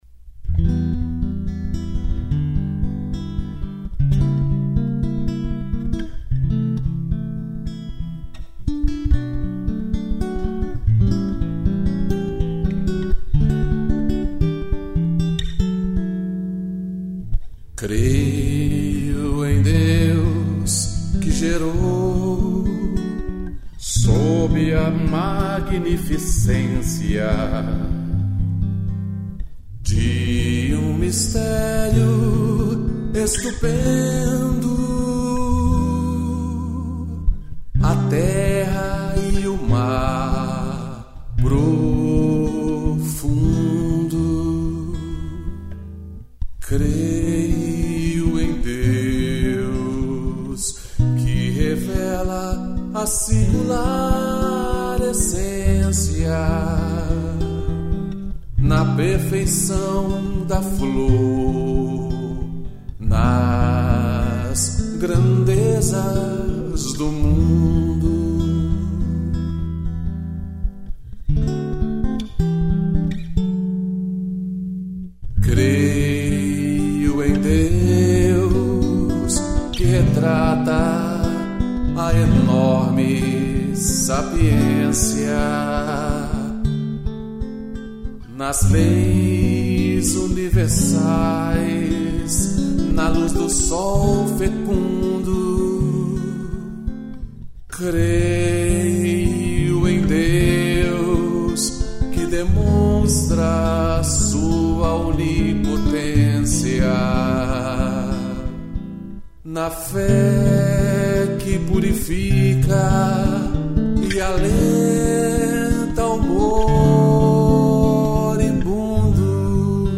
voz e violão